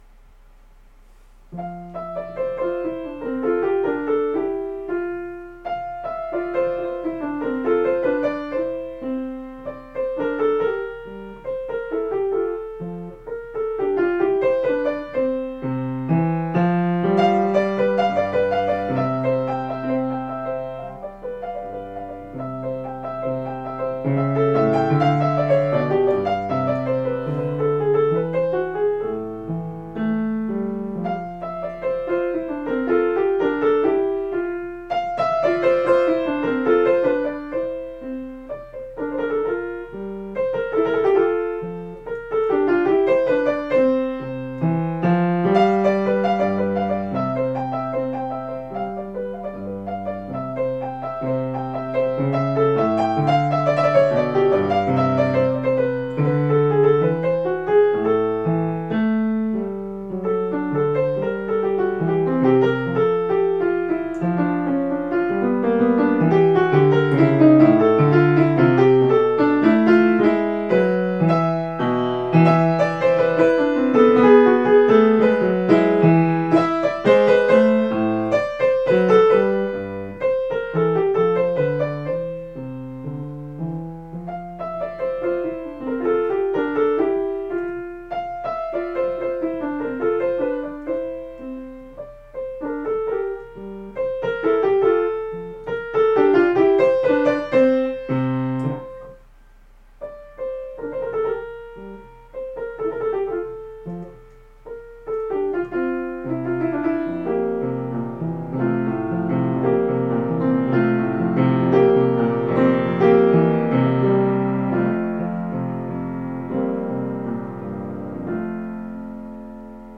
Here is an informal recording of a Sonatina I just composed; it's an early intermediate piece around level 3-4, reminiscent of Clementi Sonatinas.
SonatinaFMajor.mp3